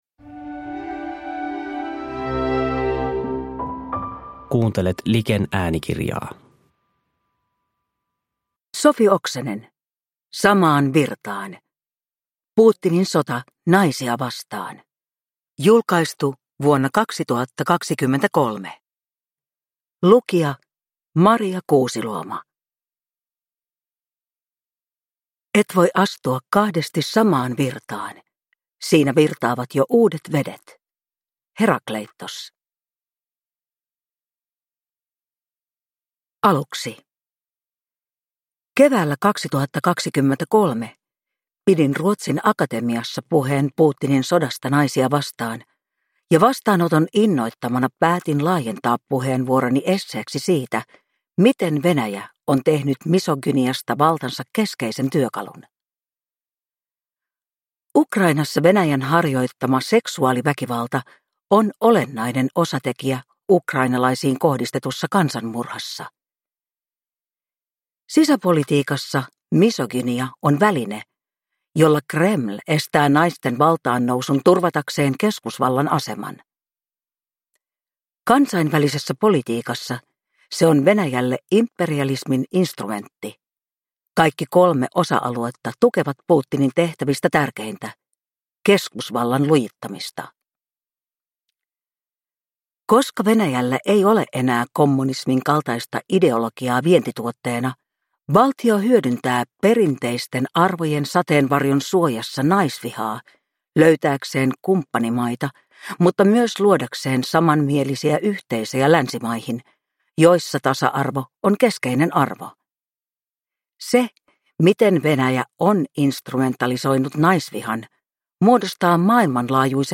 Samaan virtaan – Putinin sota naisia vastaan – Ljudbok – Laddas ner